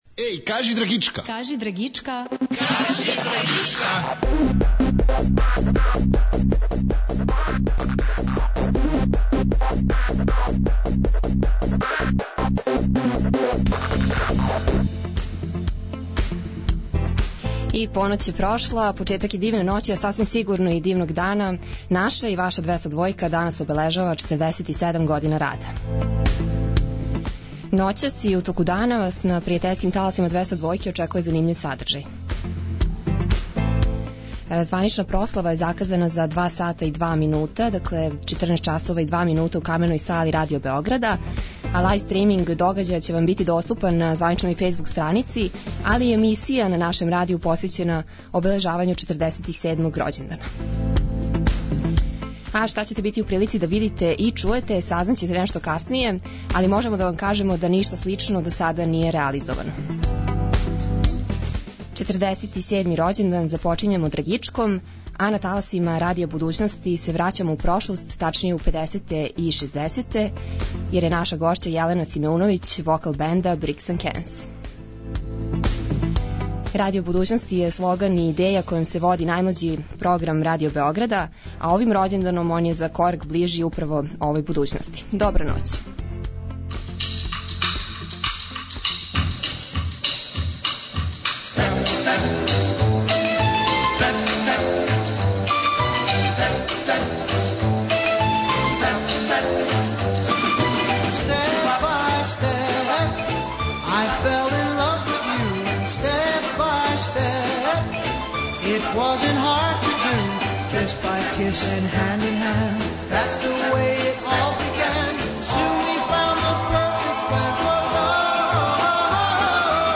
У студију је бенд Bricks & Cans!